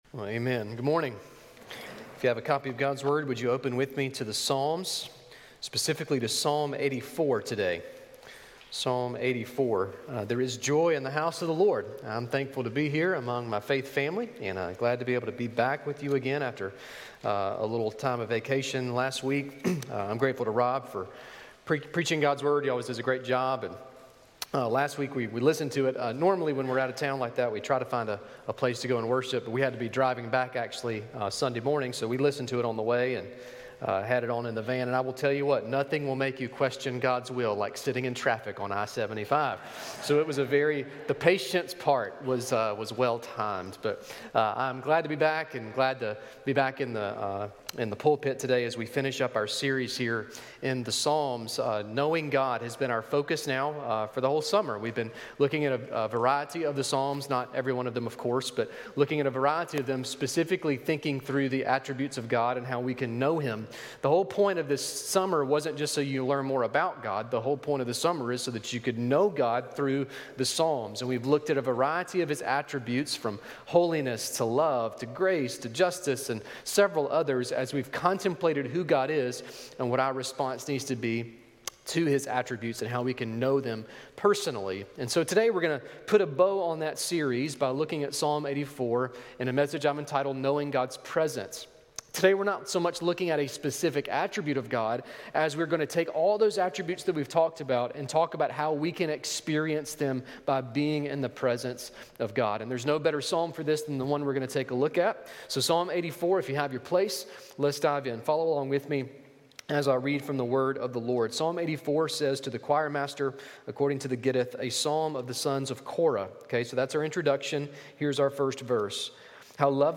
A message from the series "Go."